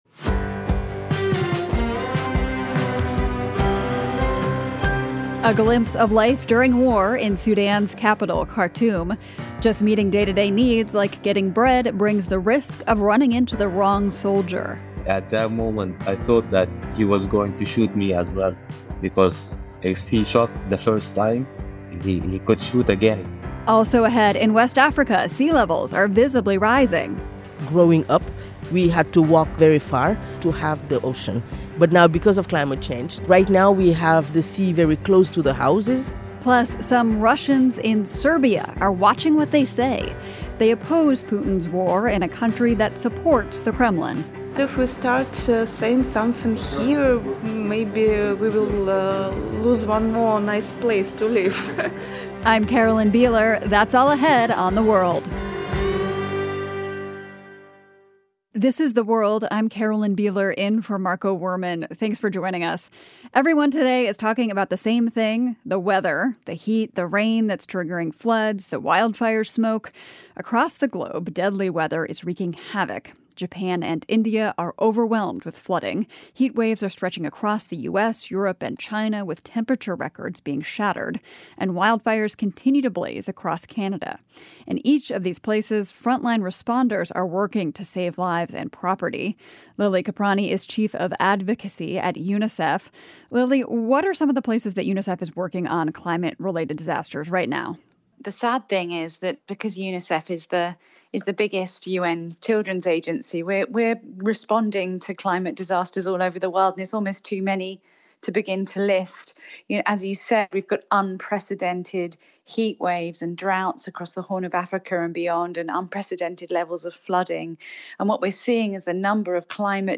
We hear from a reporter who uncovered the story. Also, climate-fueled weather systems are creating unprecedented challenges for humanitarian organizations. We speak with a first responder.
A longtime resident tells us how he survived a month of gun battles and escaped.